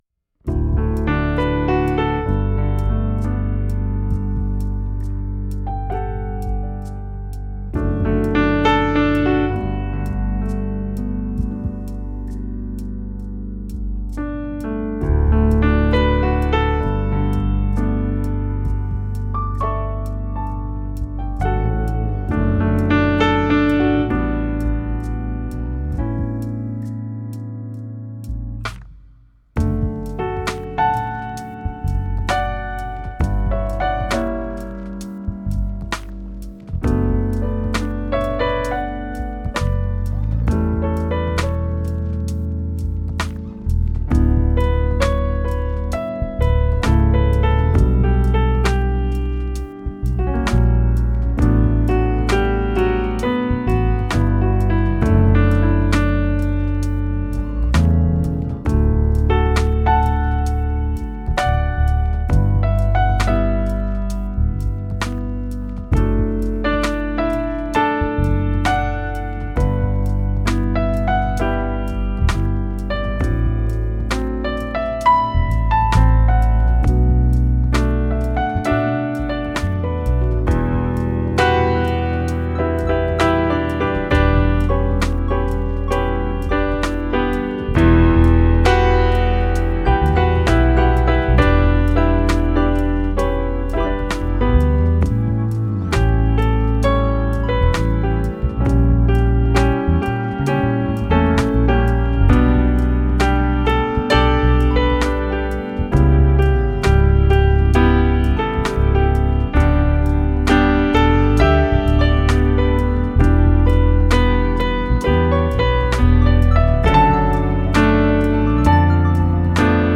Deeply sampled grand piano with a rich, versatile tone.